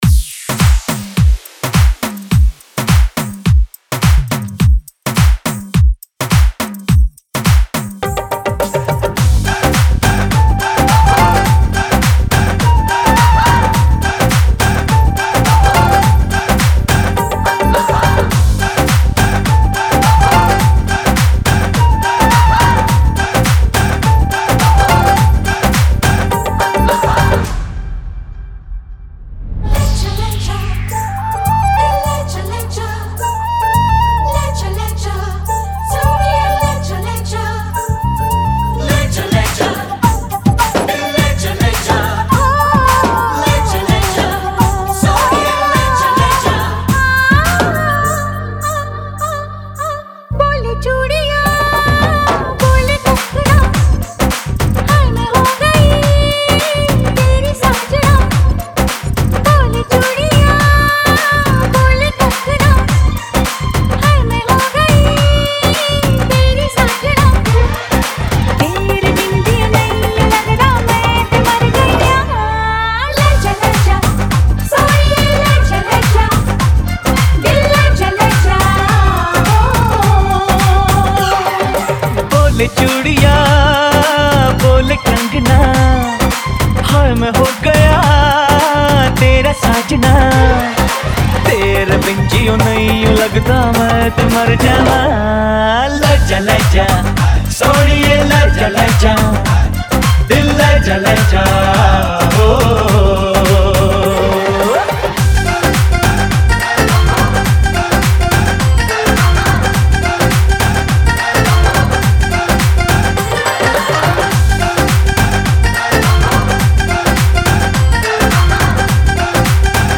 WEDDING MIX